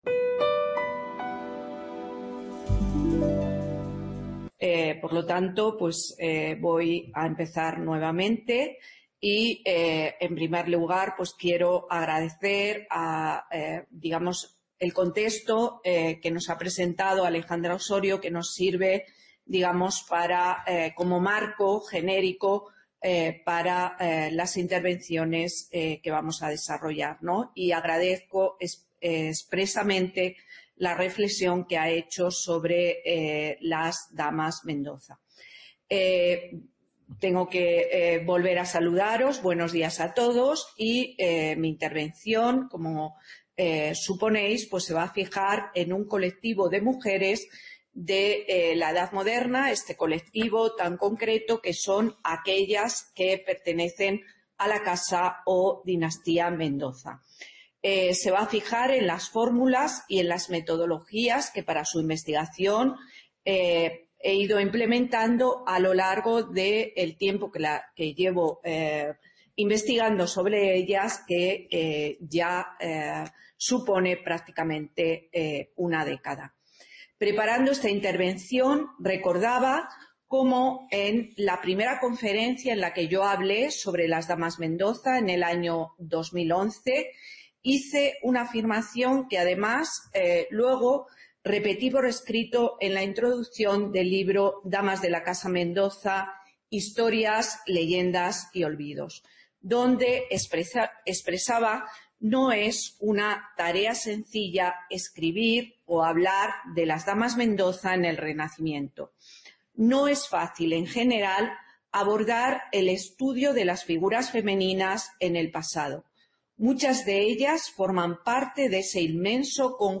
En este Seminario Web, queremos conocer y reflexionar sobre experiencias concretas de investigación que han optado por la exploración de amplios colectivos de mujeres de la Edad Moderna.